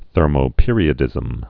(thûrmō-pîrē-ə-dĭzəm) also ther·mo·pe·ri·o·dic·i·ty (-dĭsĭ-tē)